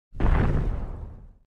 Armored titan footstep
armored-titan-footstep-1.mp3